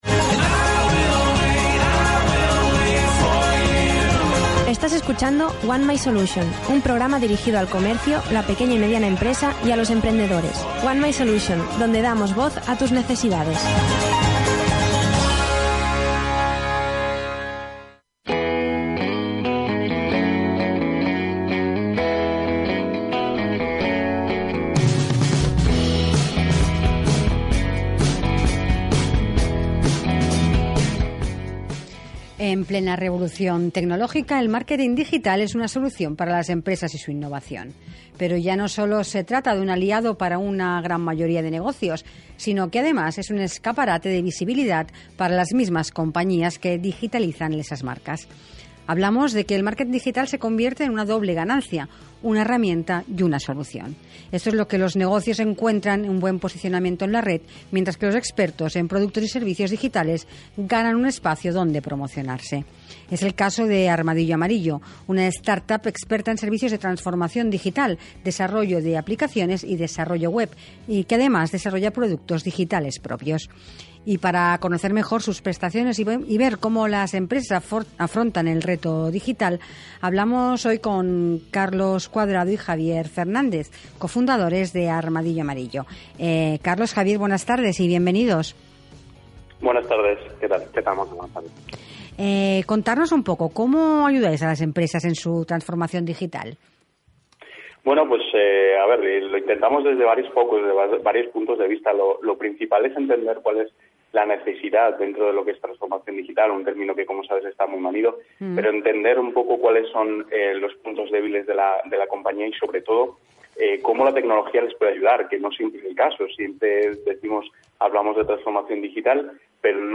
Entrevista a Armadillo Amarillo en RKB Radio Kanal Barcelona
1my Solution es un programa de radio con entrevistas de actualidad, dirigido a la pequeña y mediana empresa, al pequeño comercio y a los emprendedores.